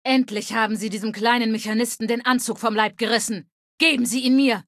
Datei:Femaleadult01default ms02 greeting 00098cd5.ogg